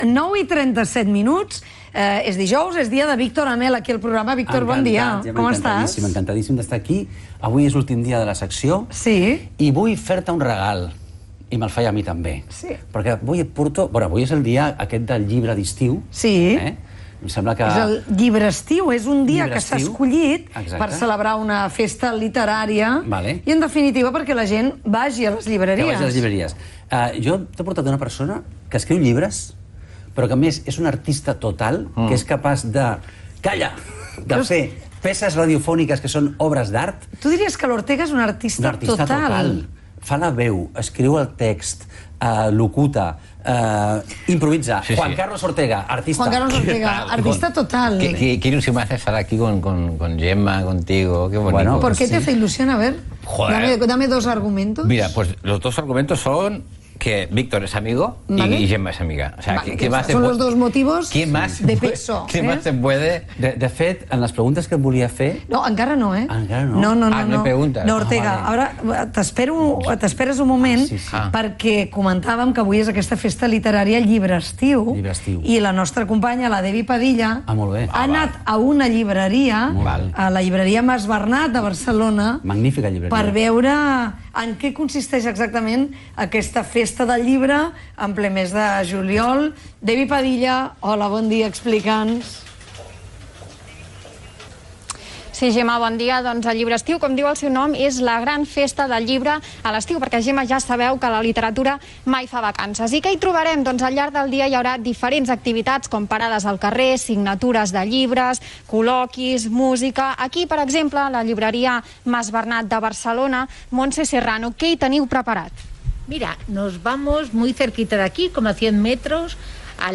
connexió amb la llibreria +Bernat de Barcelona on es celebra Llibre Estiu
entrevista a Juan Carlos Ortega
Info-entreteniment